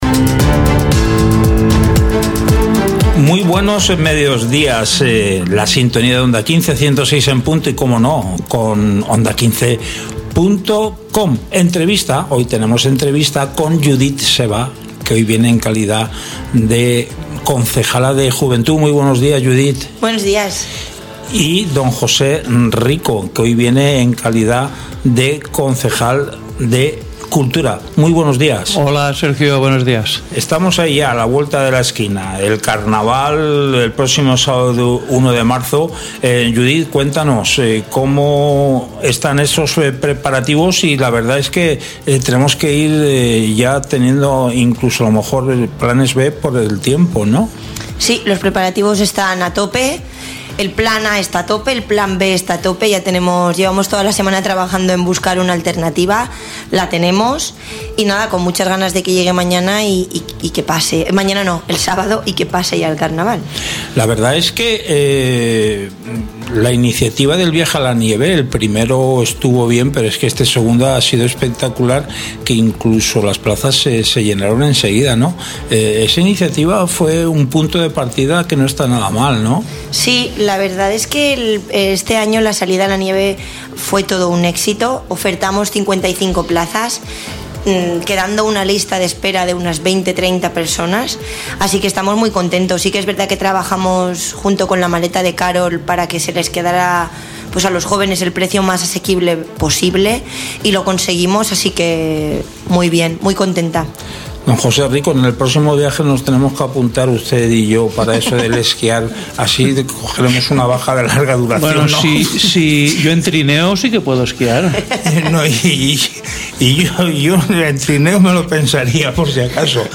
Entrevista a José Rico Bernabeu, Concejal de Cultura, y Judit Seva Cerdá, Concejala de Juventud del M.I. Ayuntamiento de Castalla - Onda 15 Castalla 106.0 FM
En el informativo de hoy, contamos con la participación de José Rico Bernabeu, Concejal de Cultura, y Judit Seva Cerdá, Concejala de Juventud del M.I. Ayuntamiento de Castalla. A lo largo de la entrevista, los ediles detallan la programación de actividades organizadas para la celebración del Carnaval este fin de semana.